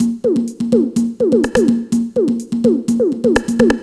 Index of /musicradar/retro-house-samples/Drum Loops
Beat 18 No Kick (125BPM).wav